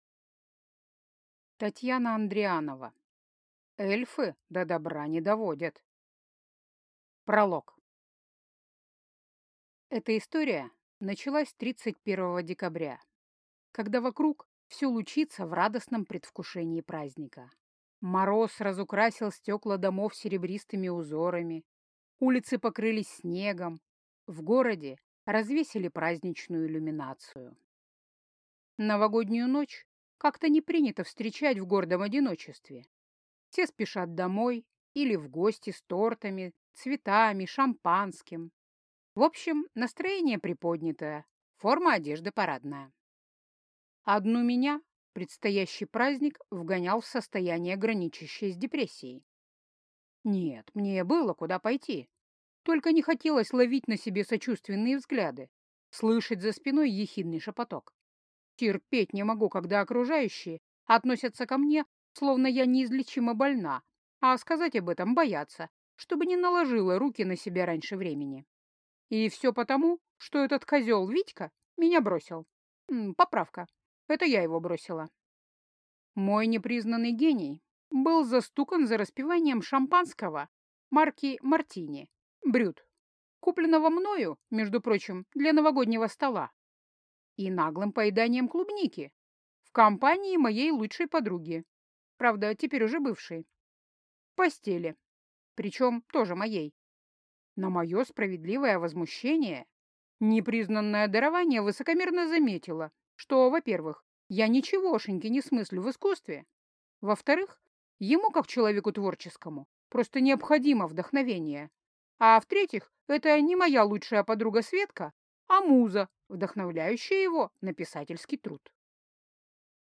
Аудиокнига Эльфы до добра не доводят | Библиотека аудиокниг
Прослушать и бесплатно скачать фрагмент аудиокниги